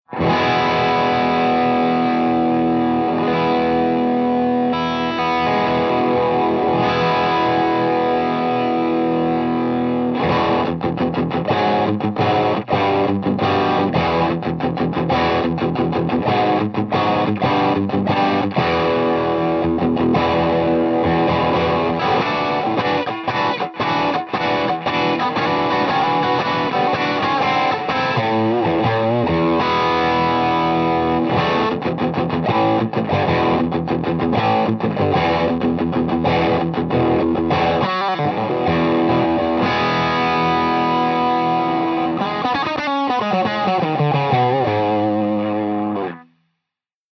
024_BUCKINGHAM_FUZZ_P90.mp3